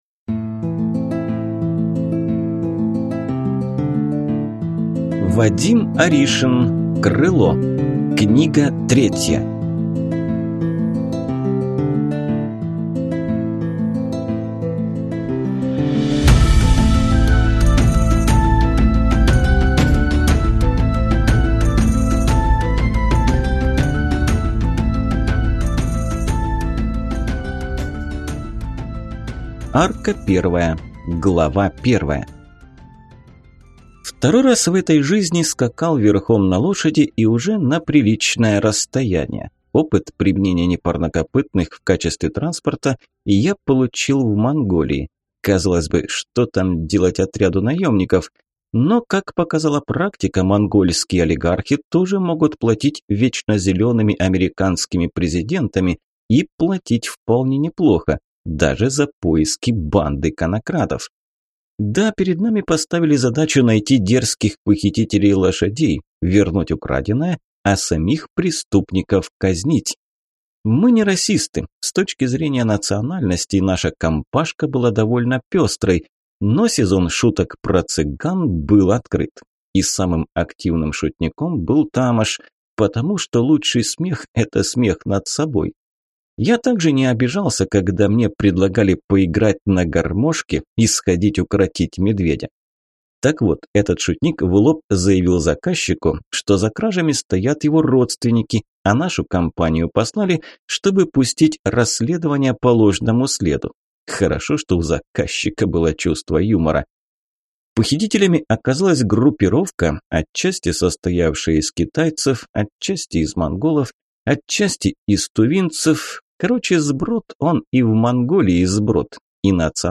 Aудиокнига Крыло.